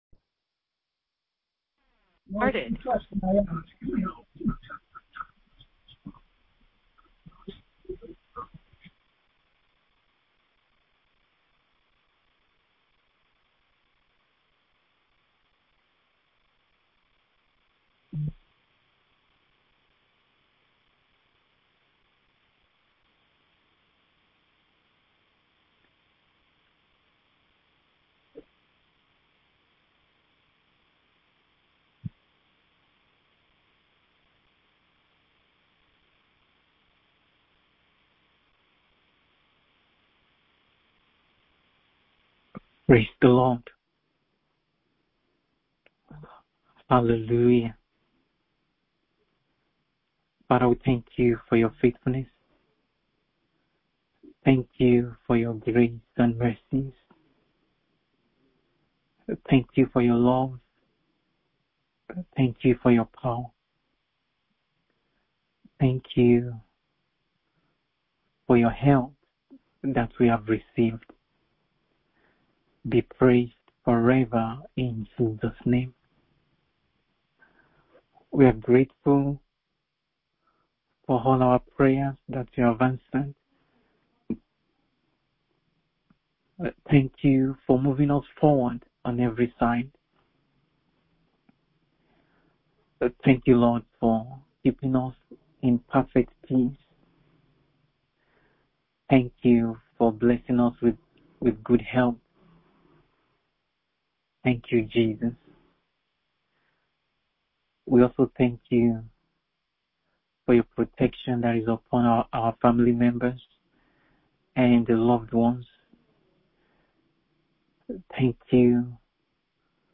BIBLE STUDY CLASS